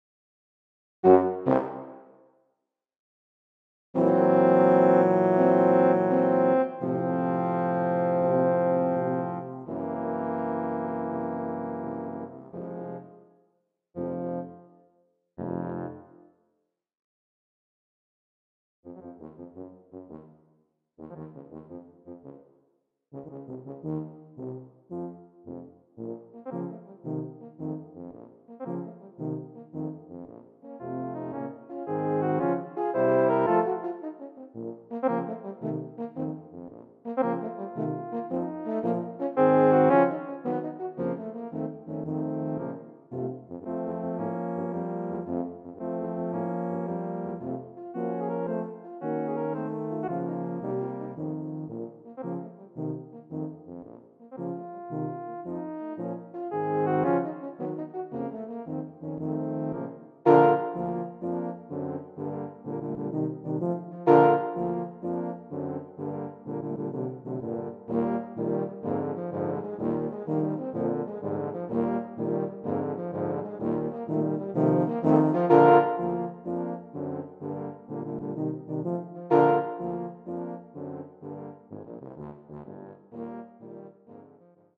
Voicing: Tuba / Euphonium Quartet